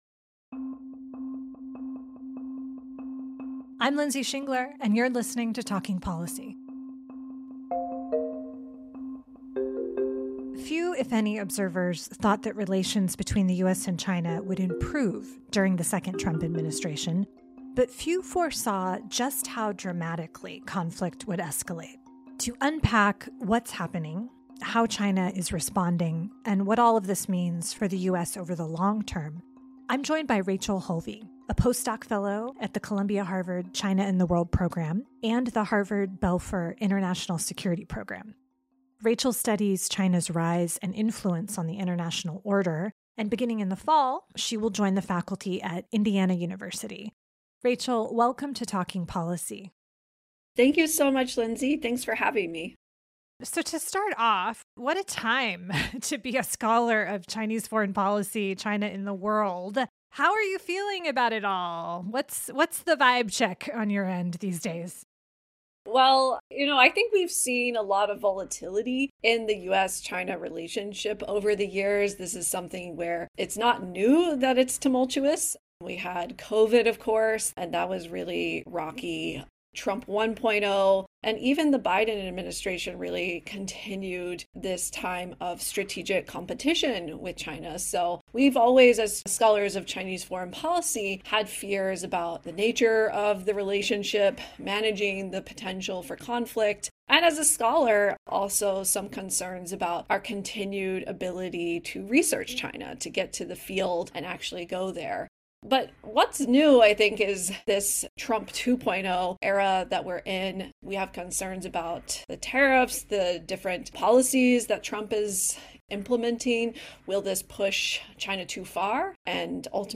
This interview was conducted on April 16, 2025 and has been edited for length and clarity.